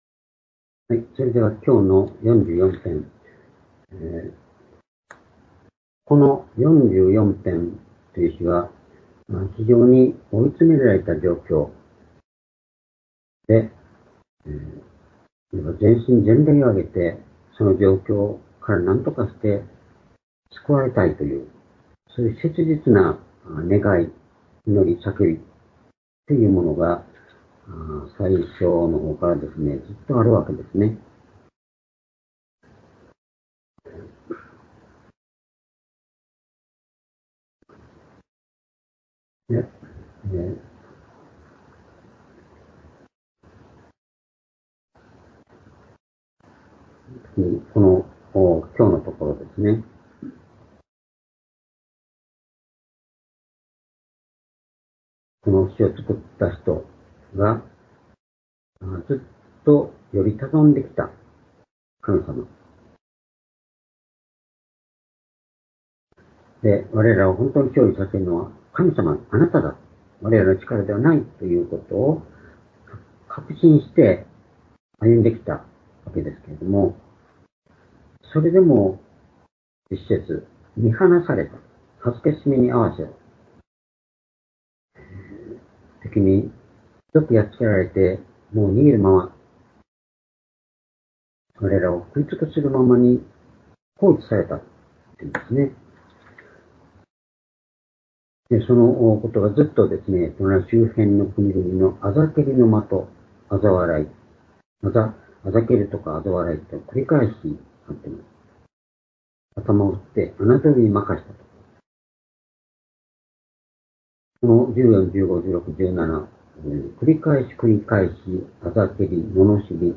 「深き闇の中にての歩み」詩編44編10～19節-2024年8月20日(夕拝)